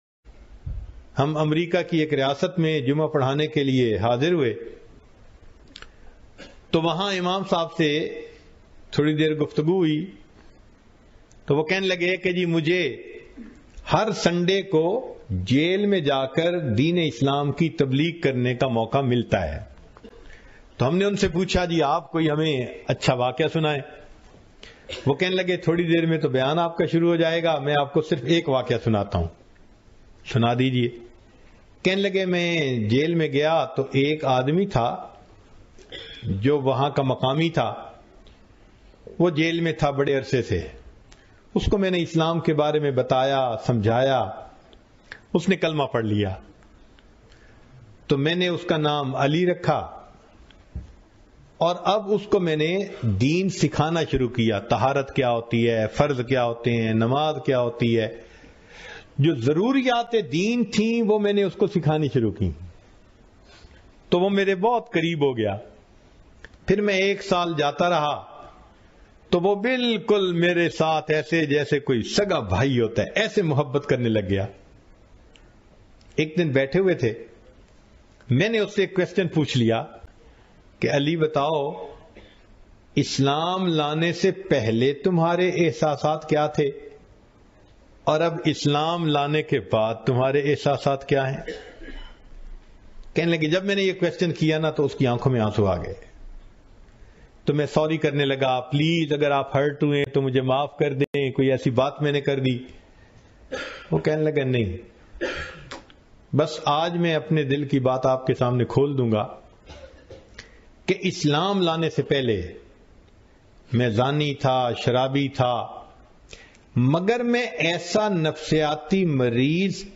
Story of an American who was in Jail bayan mp3